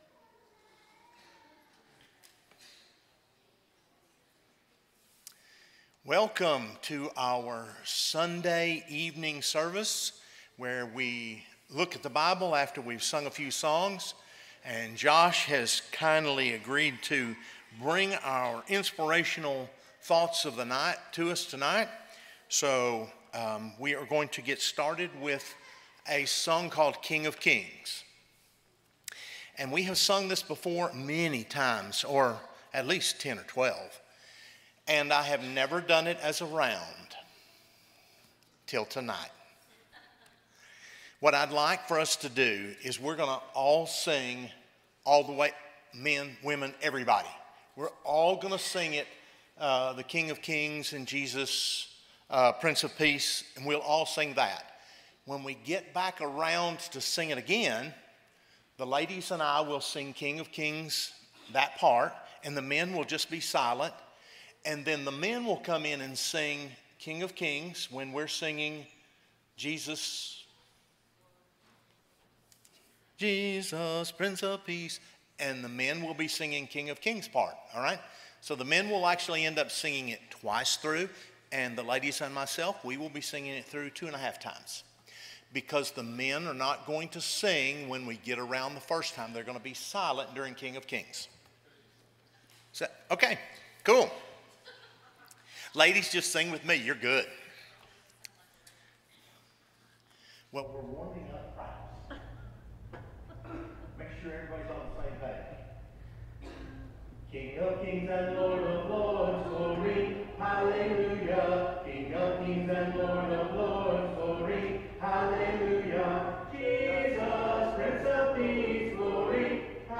But whoever would be great among you must be your servant,” Matthew 20:26, English Standard Version Series: Sunday PM Service